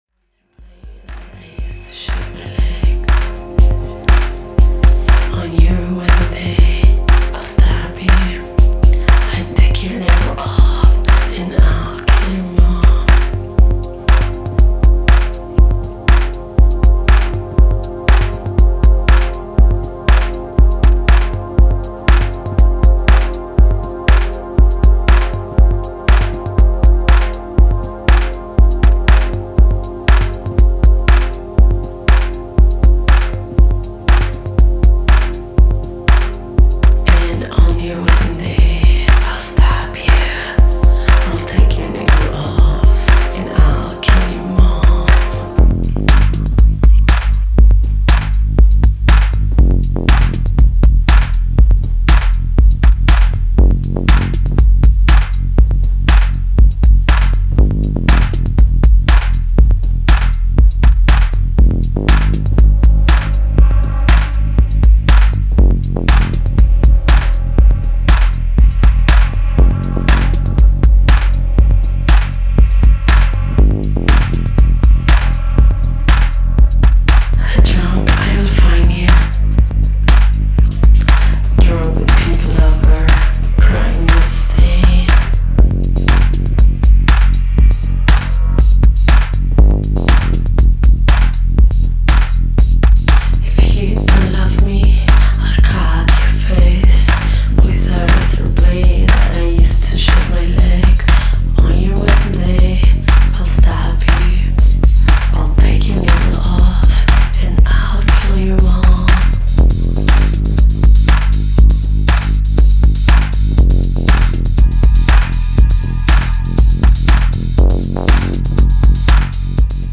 Four solid cuts of deep Electro/ Wave tracks.